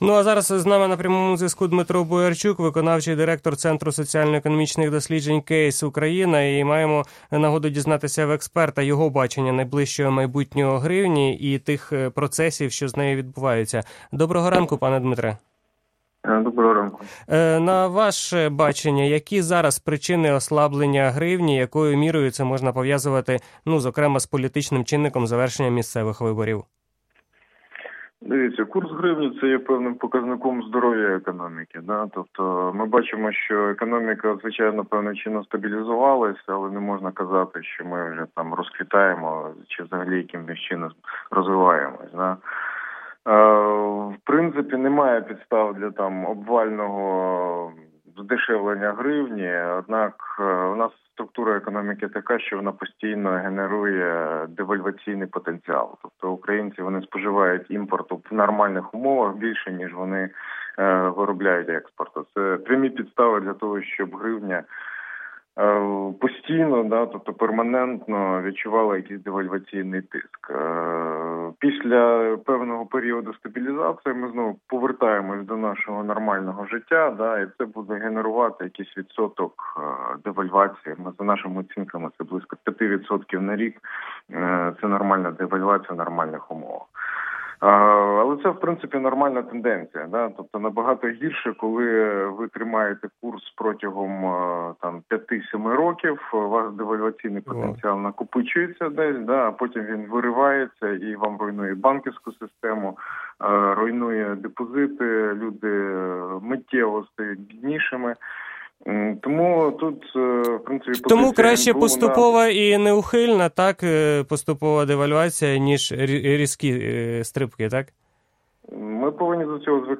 відео Радіо Свобода